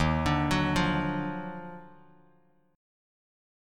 D#mM9 Chord